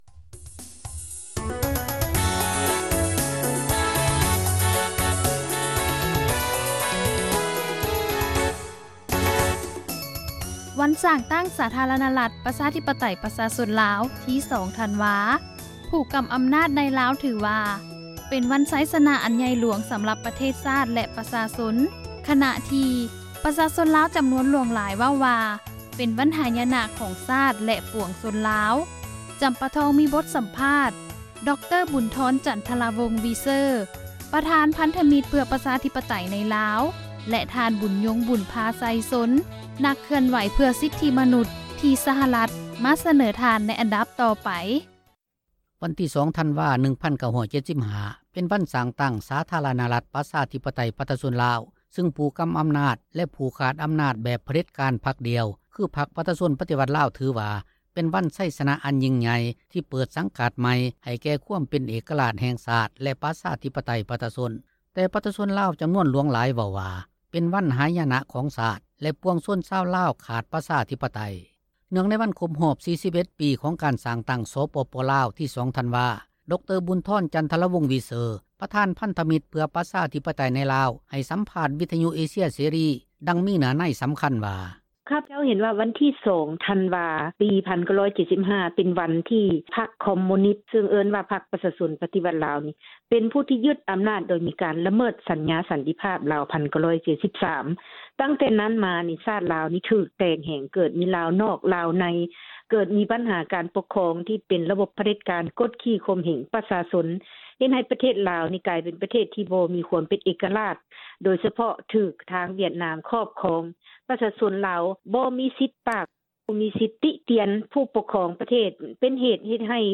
ບົດສຳພາດ